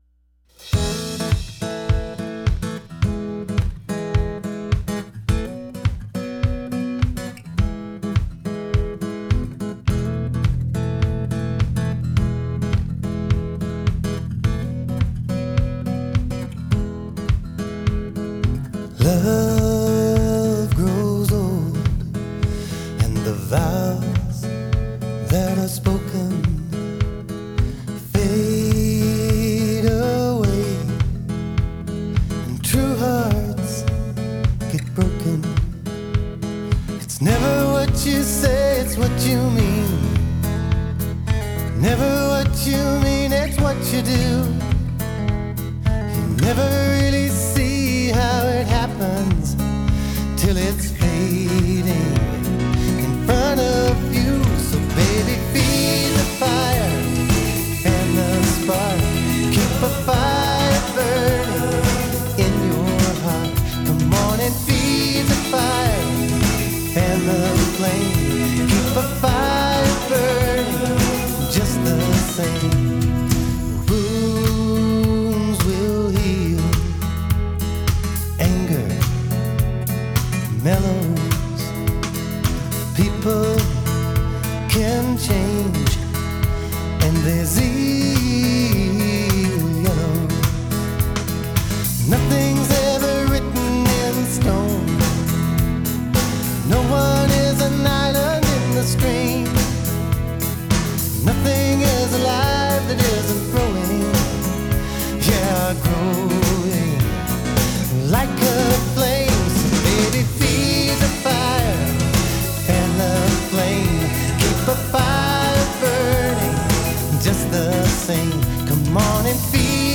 Pop / RockLove/RelationshipTime PassingUp Tempo